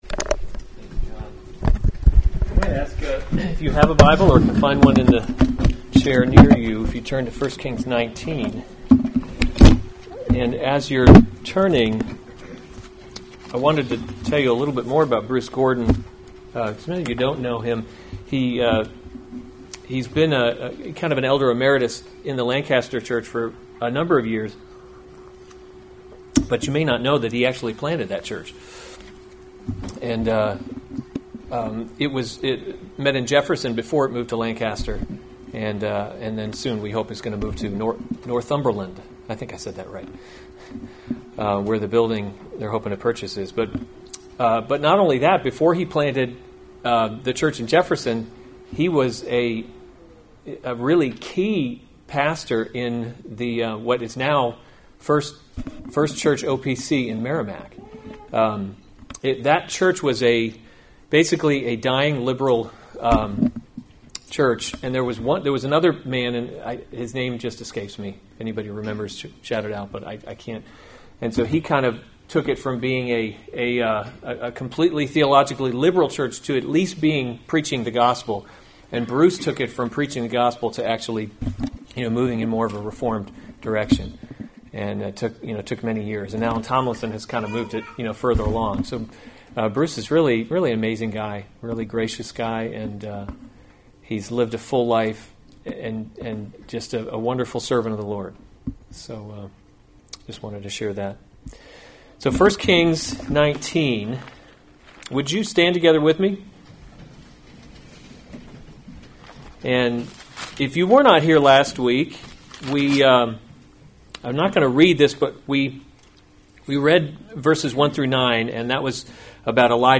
June 22, 2019 1 Kings – Leadership in a Broken World series Weekly Sunday Service Save/Download this sermon 1 Kings 19:9b-18 Other sermons from 1 Kings The Lord Speaks to […]